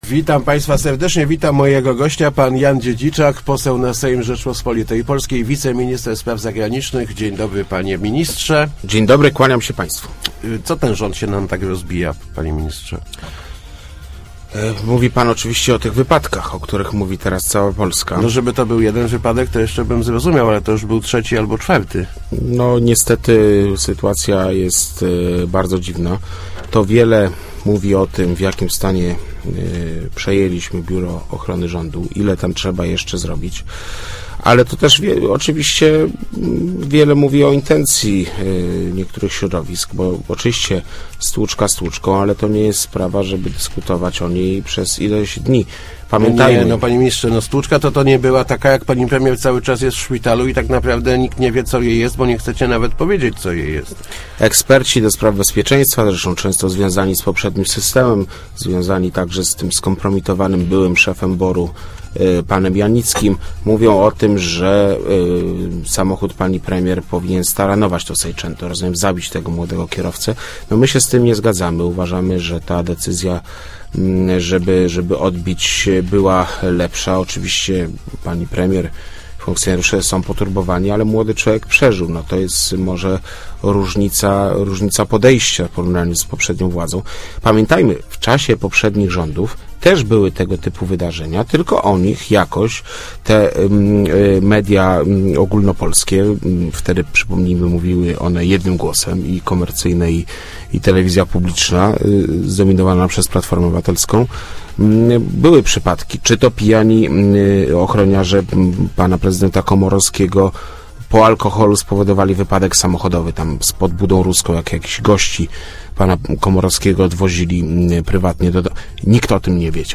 W czasie poprzednich rządów też dochodziło do wypadków z udziałem rządowych samochodów, tylko wtedy o nich się nie informowało - mówił w Rozmowach Elki poseł Jan Dziedziczak, wiceminister Spraw Zagranicznych. Jak zapewnił, premier i prezydent nie mogą zrezygnować z ochrony BOR nawet podczas prywatnych przejazdów.